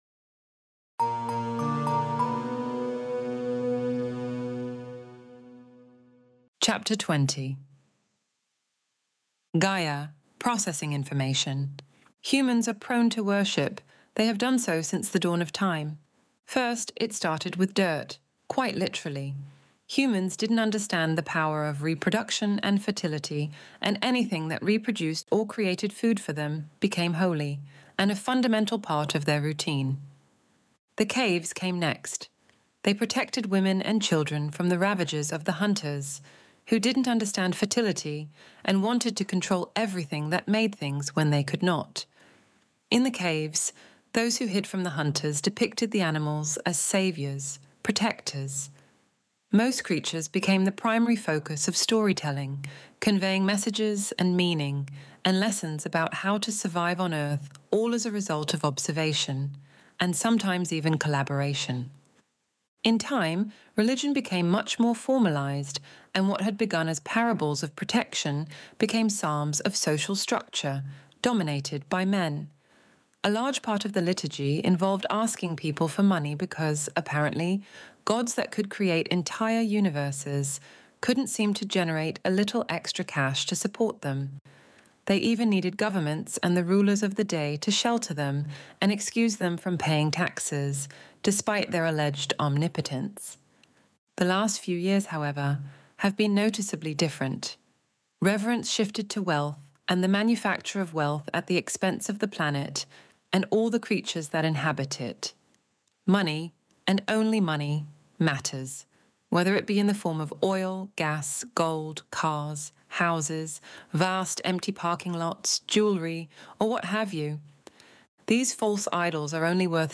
Extinction Event Audiobook Chapter 20